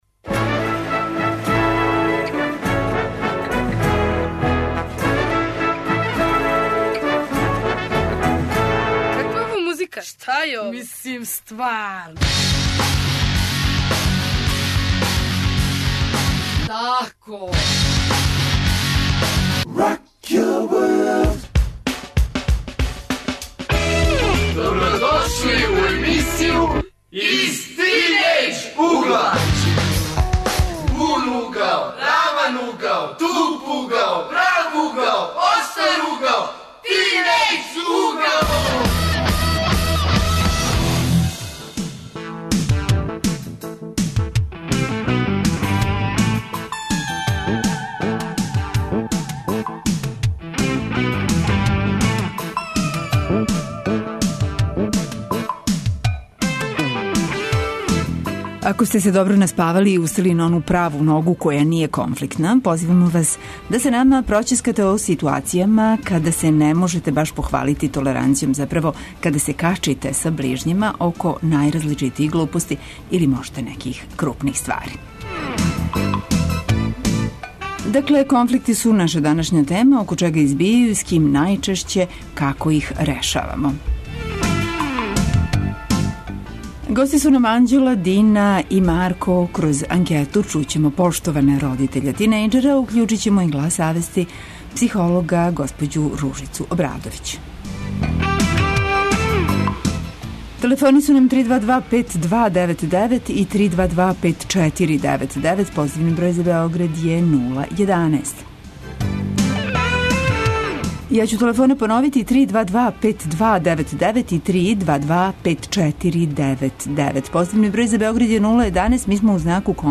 Наши гости су тинејџери и од њих ћемо чути због чега су они у конфликту с околином, родитељима, међусобно и како их решавају. И како на конфликт тинејџера гледају родитељи и психолози.